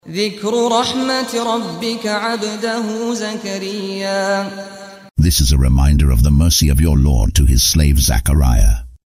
Audio version of Surah Maryam ( Mary ) in English, split into verses, preceded by the recitation of the reciter: Saad Al-Ghamdi.